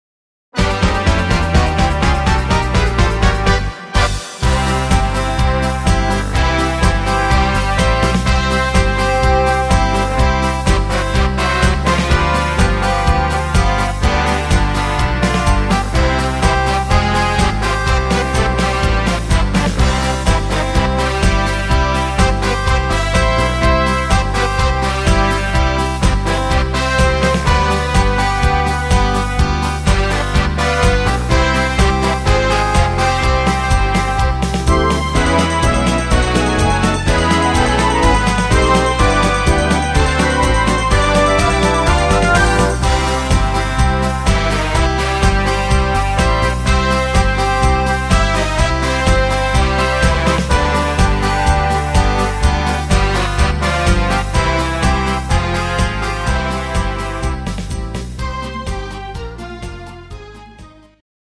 instr. Big Band